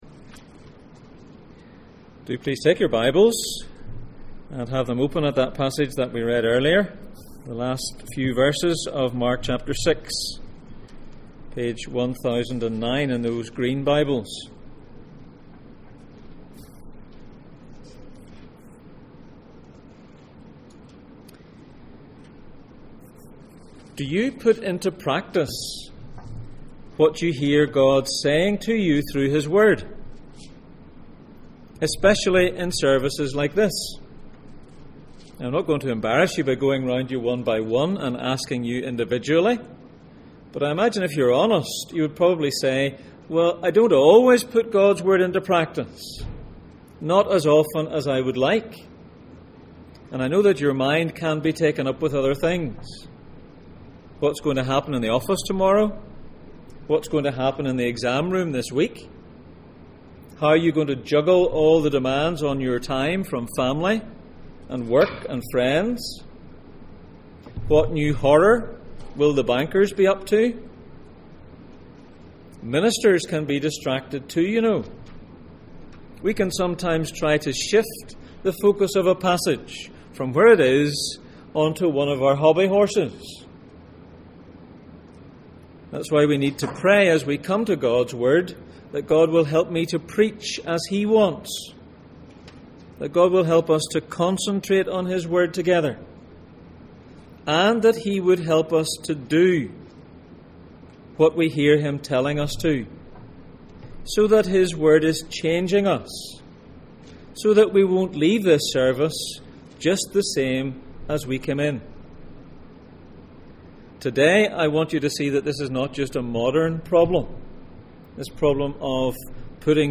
Jesus in Mark Passage: Mark 6:45-56 Service Type: Sunday Morning %todo_render% « Can a piece of bread give you eternal life?